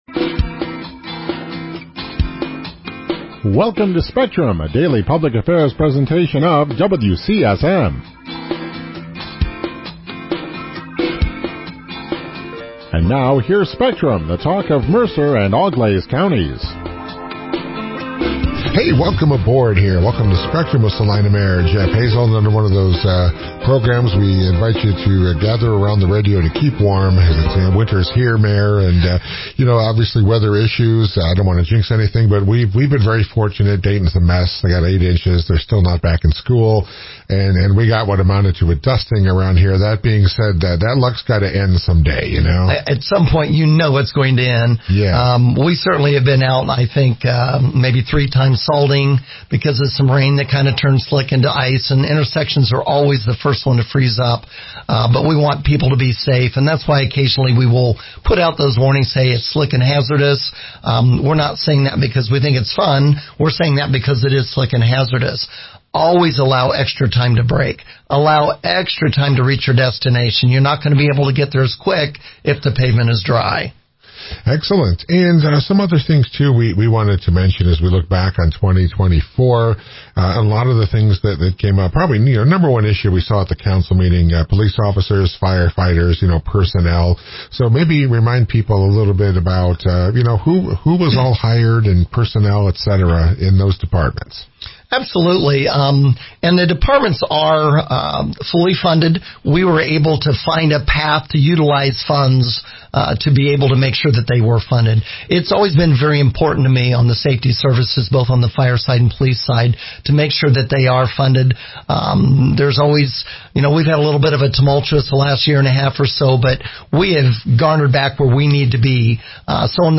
The following is an AI Generated summary of the Mayor's appearance on Spectrum: